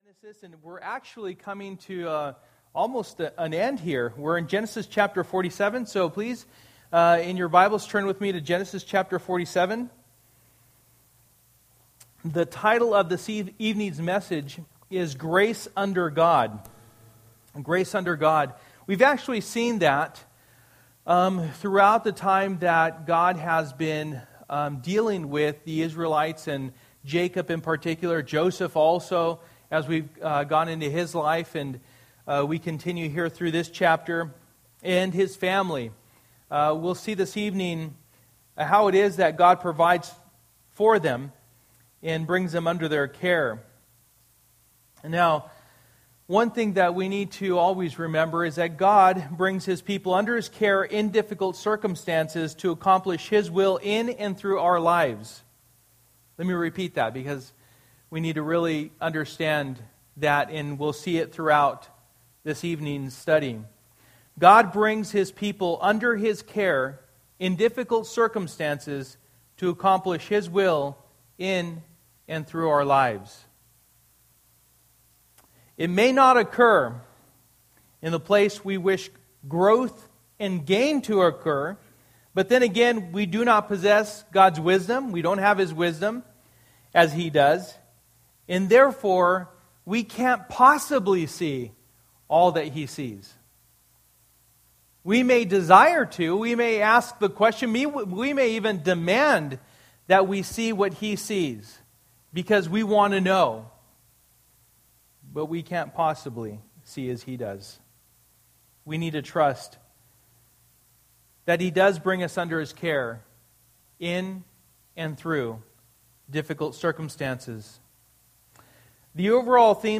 Through the Bible Service: Wednesday Night %todo_render% « What Happens in Pergamum Stays in Pergamum Show Her the Door